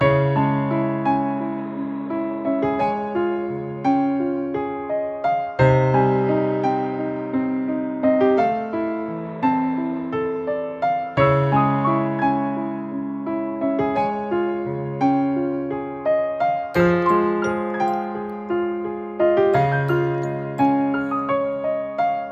Tag: 86 bpm Cinematic Loops Piano Loops 3.76 MB wav Key : C